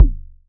edm-perc-09.wav